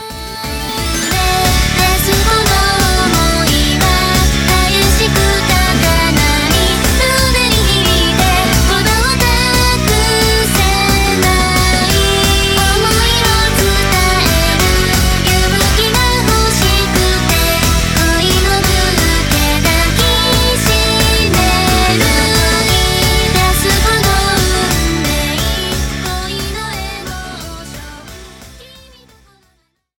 アイドルPOP